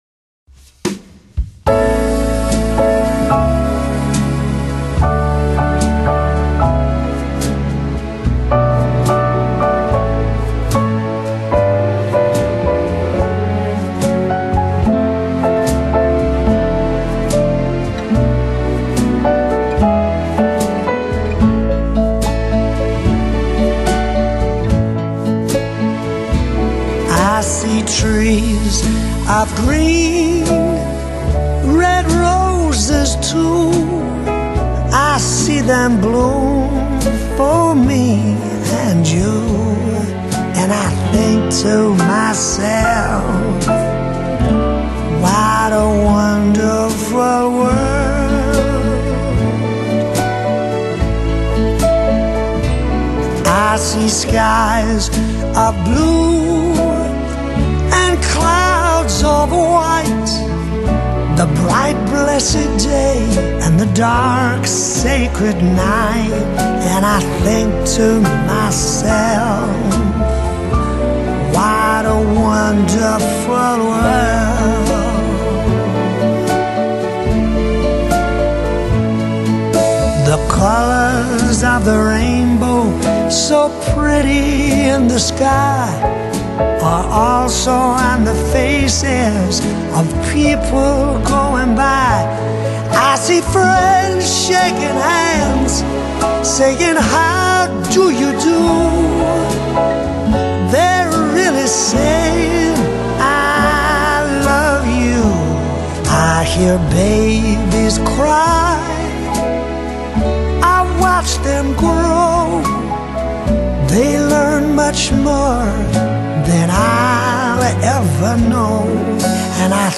以風格統一的性感白人爵士，演唱世間男女傳唱的愛戀情歌經典。